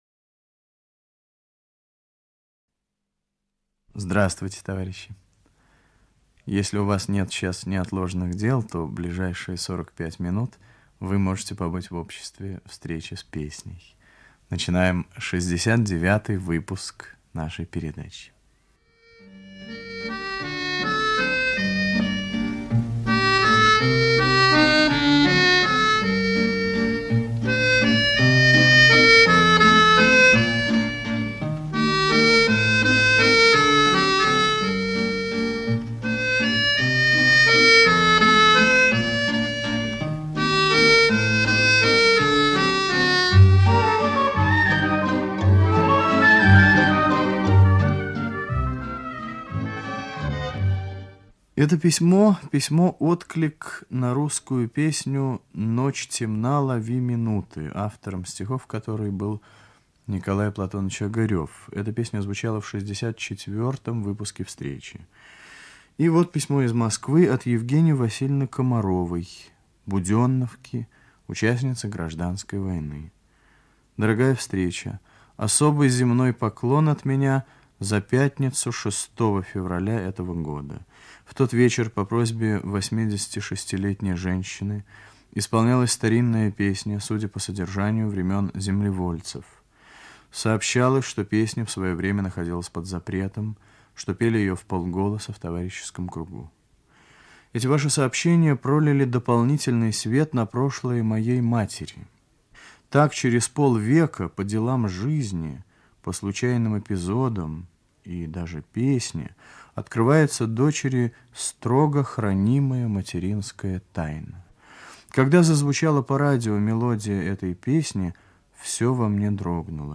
Ведущий - автор, Виктор Татарский.
1 Революционная песня